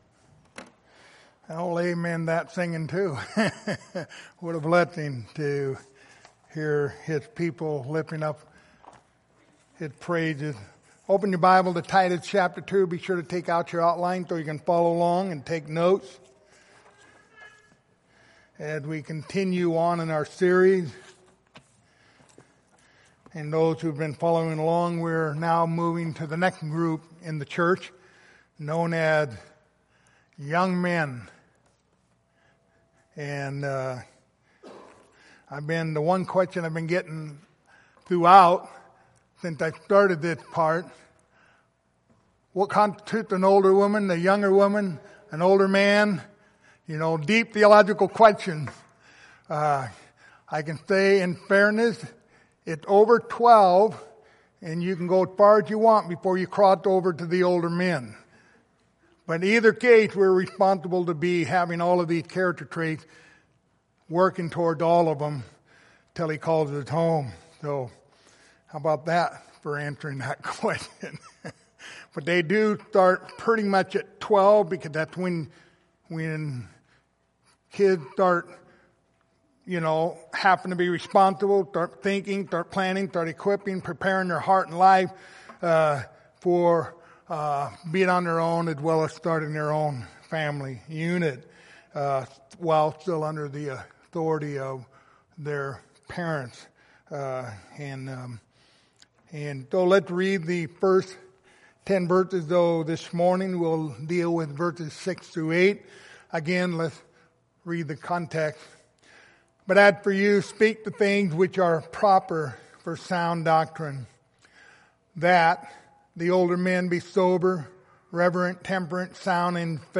Passage: Titus 2:6-8 Service Type: Sunday Morning